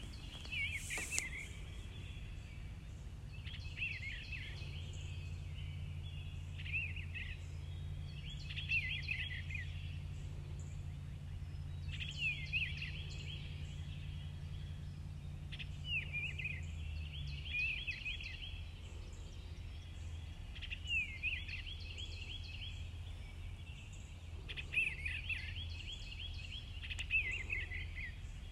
birds-chirping-march-22.m4a